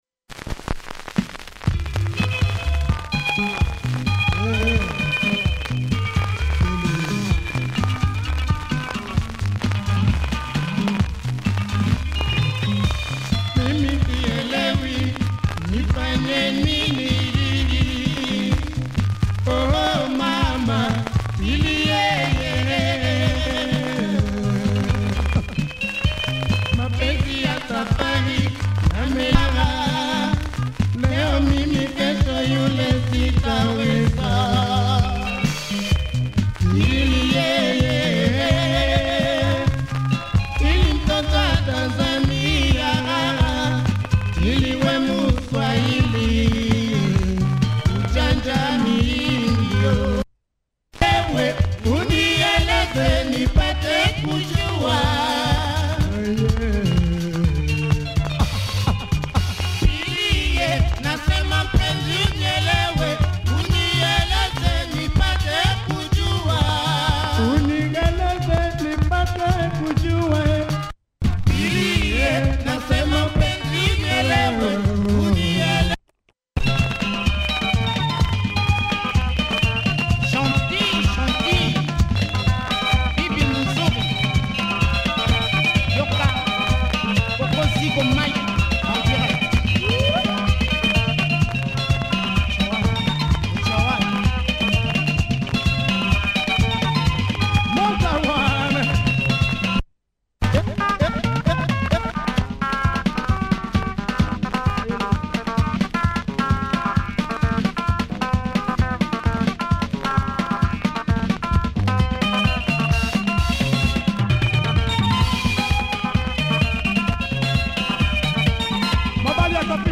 disc has lots of wear and plays thereafter, check audio!